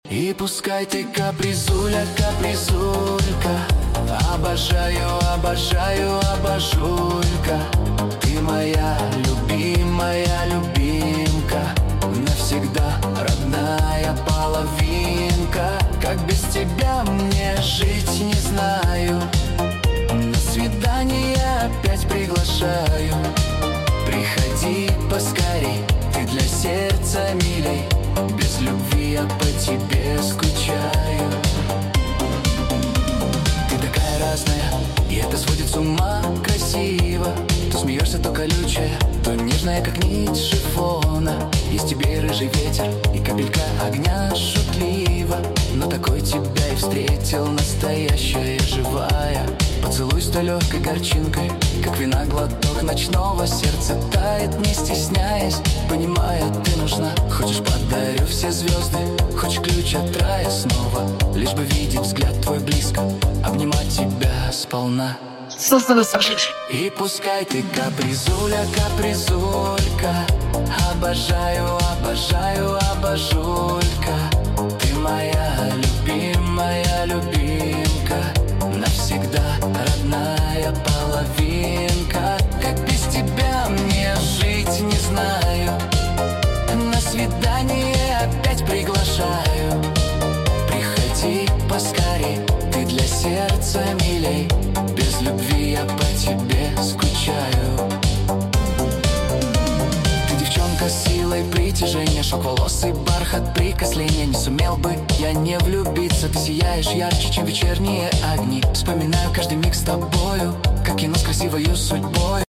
Русская AI музыка